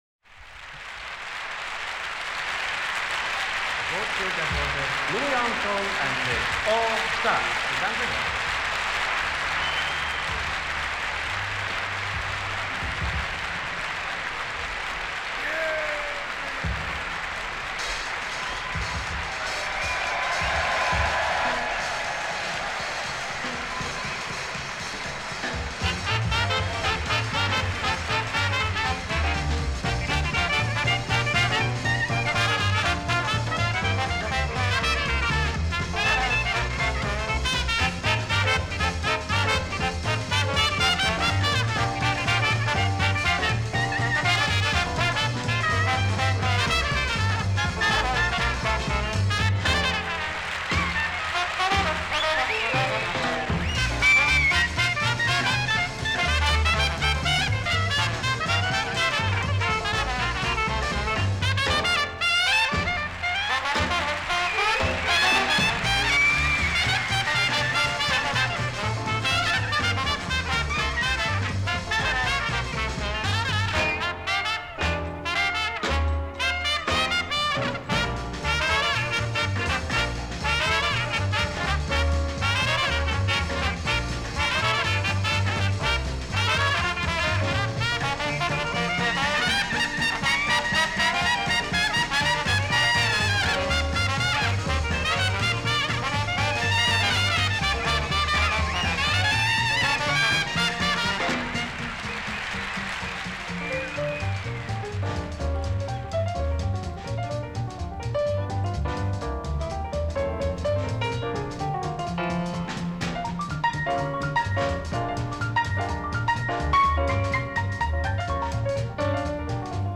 recorded live during a European tour
lush clarinet tones and incomparably mellifluous lines
rock solid bass